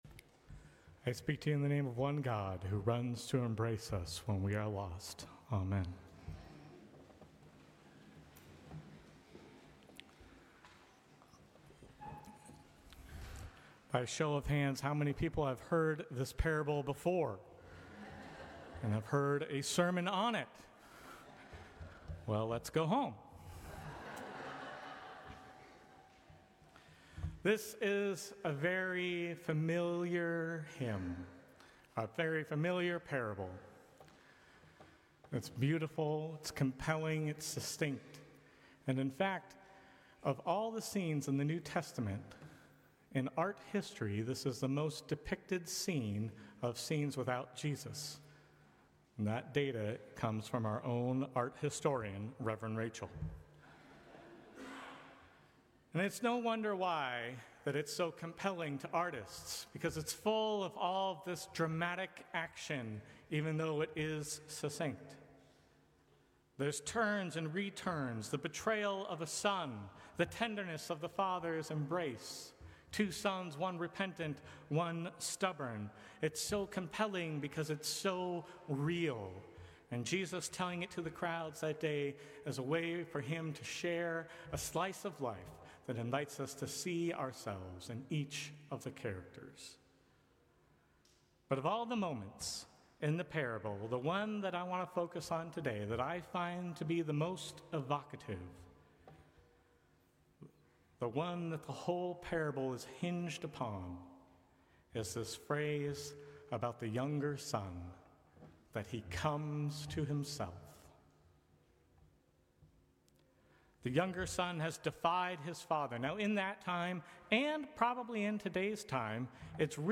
Sermons from St. Cross Episcopal Church Fourth Sunday in Lent Mar 30 2025 | 00:12:10 1x 00:00 / 00:12:10 Subscribe Share Apple Podcasts Spotify Overcast RSS Feed Share Link Embed